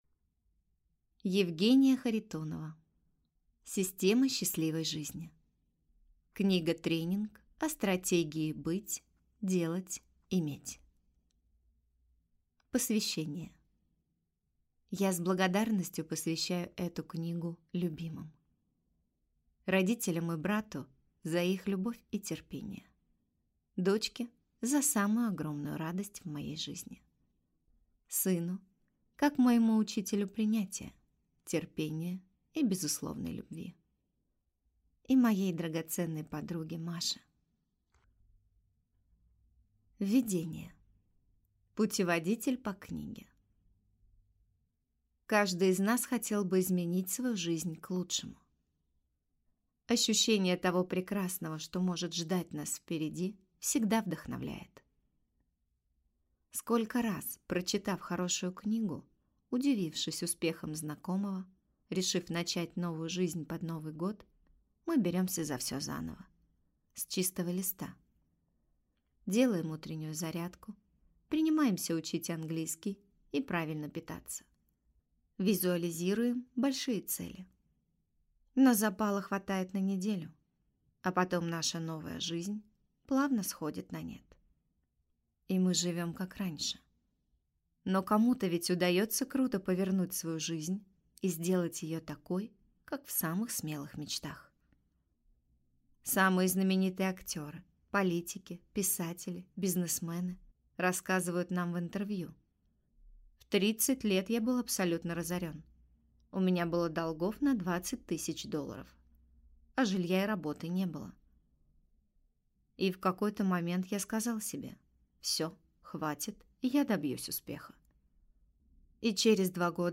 Аудиокнига Система счастливой жизни. Книга-тренинг о стратегии «Быть-делать-иметь» | Библиотека аудиокниг